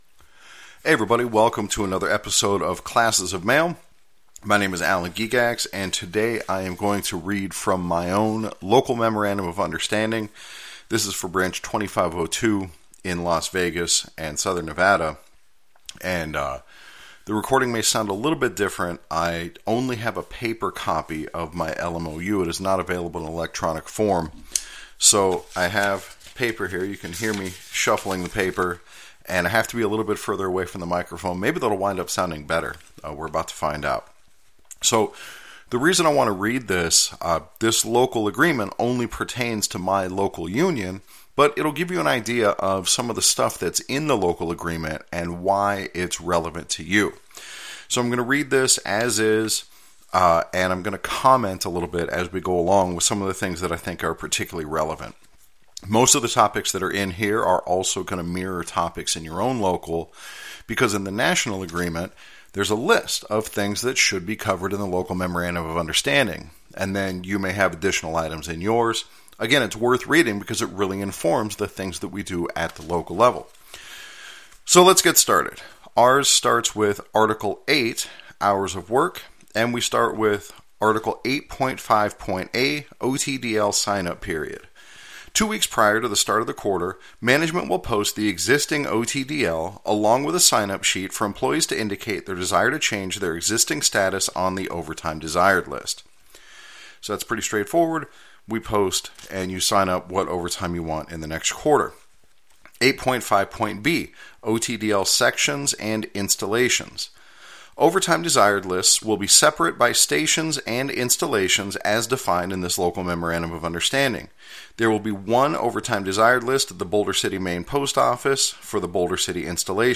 Today I will read from my own LMOU.